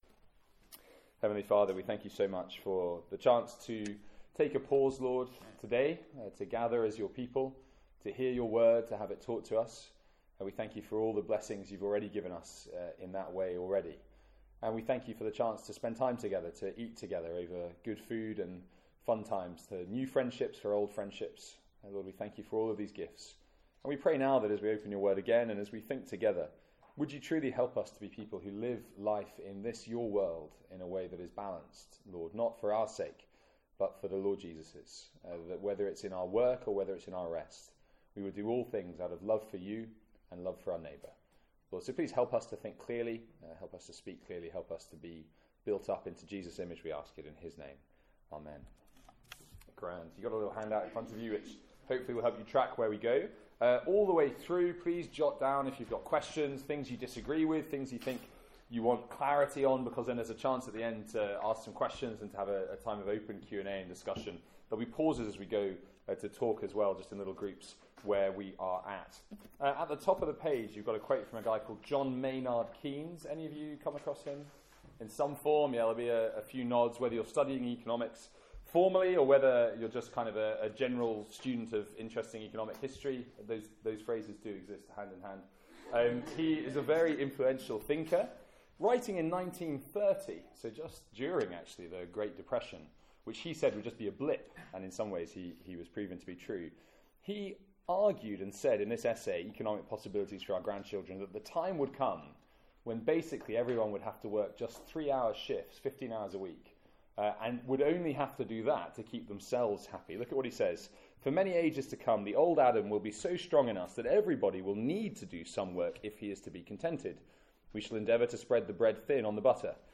From our first student lunch of the academic year.
Note – Group discussion editted out and question time begins at 40min.